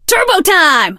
max_start_vo_05.ogg